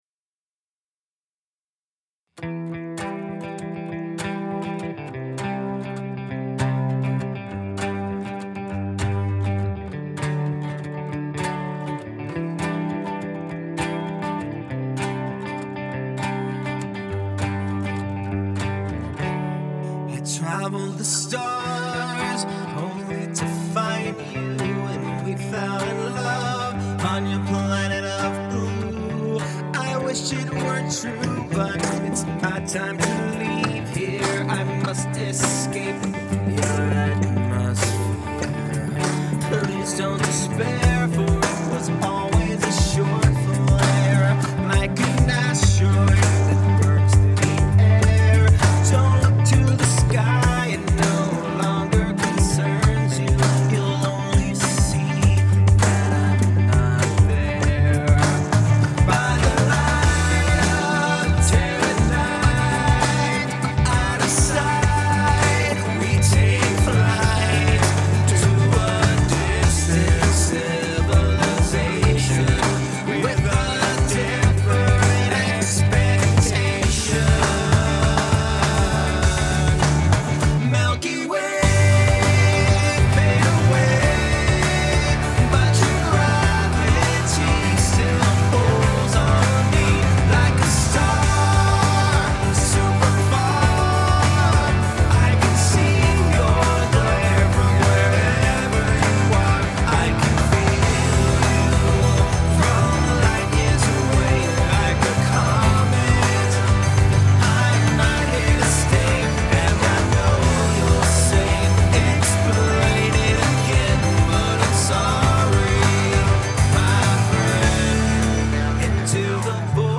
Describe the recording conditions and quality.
Early Demo